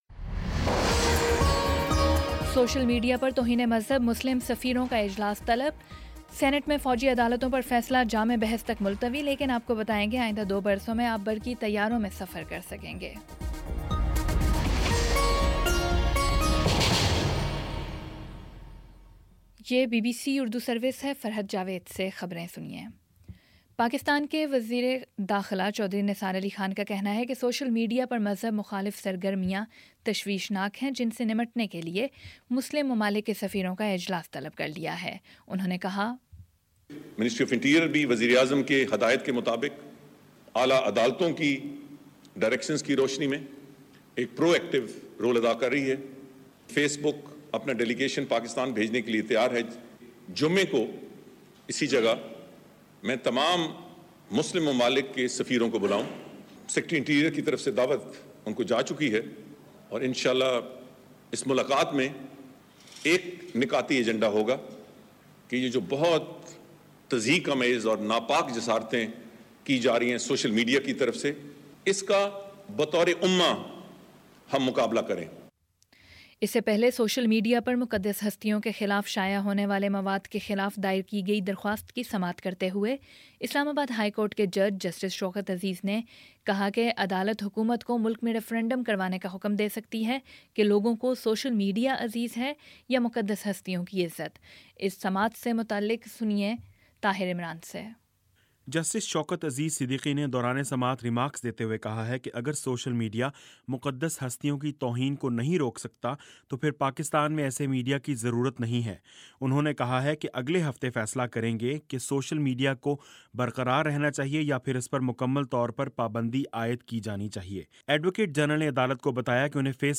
مارچ 22 : شام چھ بجے کا نیوز بُلیٹن